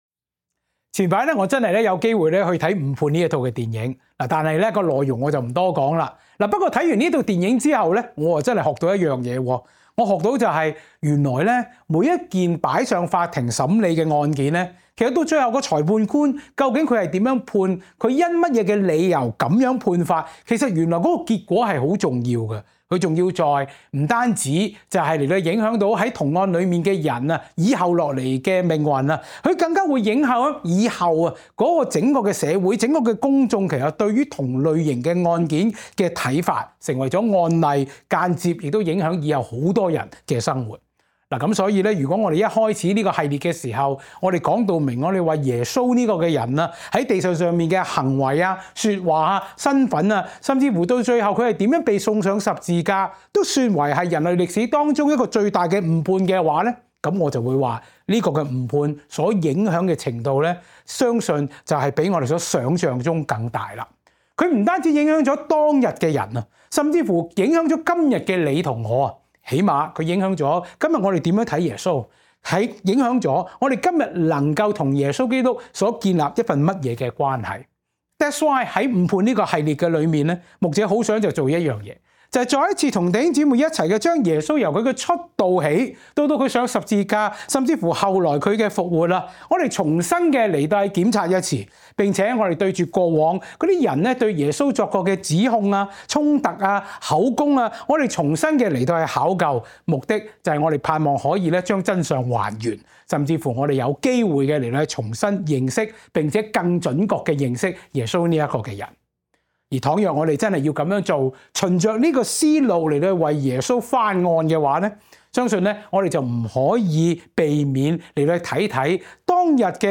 講道搜尋 | Koinonia Evangelical Church | 歌鄰基督教會